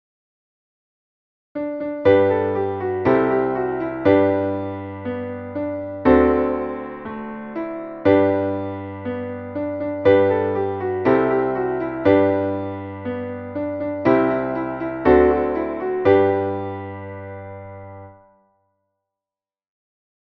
Traditionelles Volkslied / Kinderlied